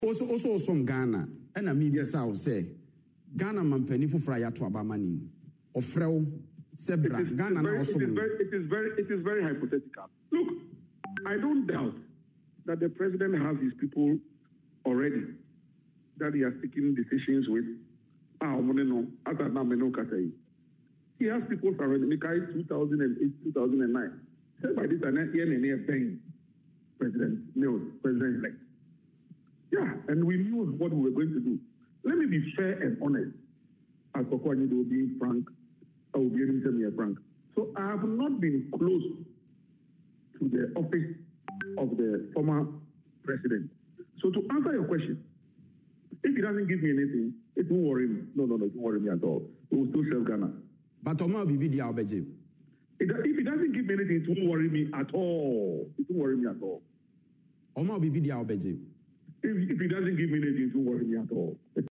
Mr Anyidoho said this in an interview on Adom FM/TV following Mr Mahama’s victory in the just-ended 2024 election.